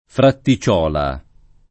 Fratticiola [ fratti ©0 la ]